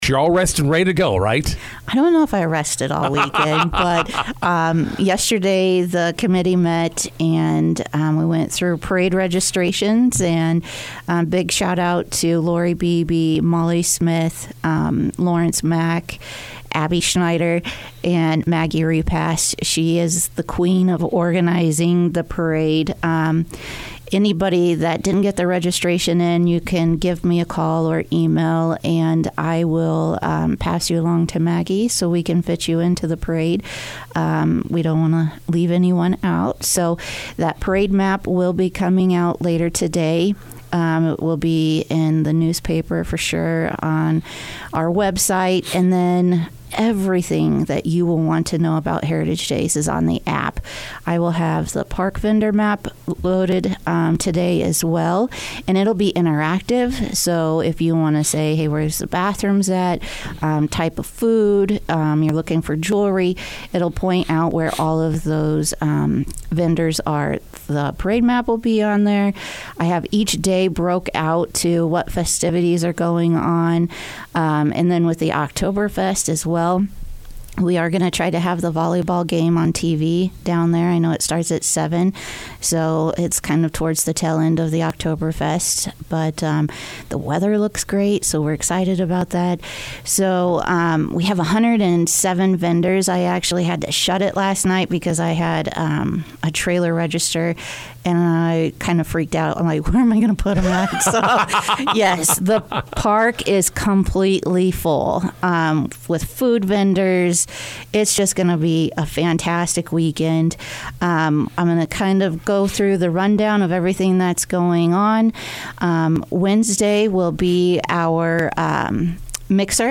INTERVIEW: Heritage Days activities begin tonight in McCook.